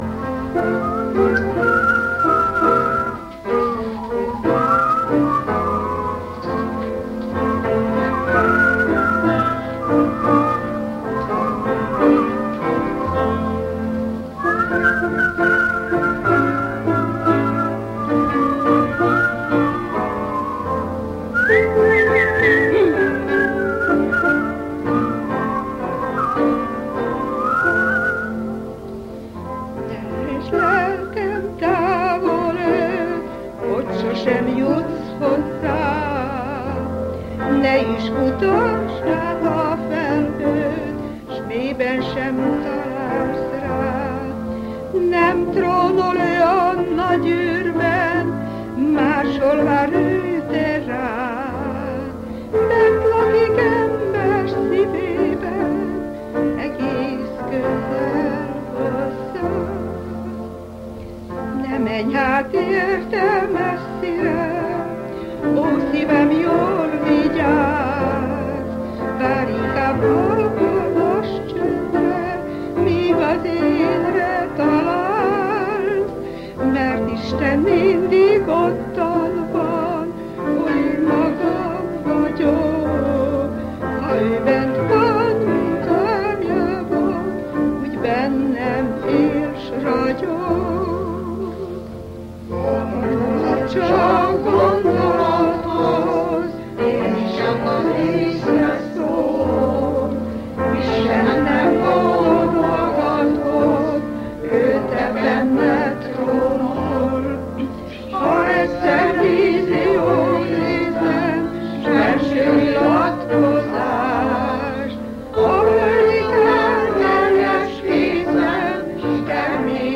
Magyar énekléssel (1990-es felvétel):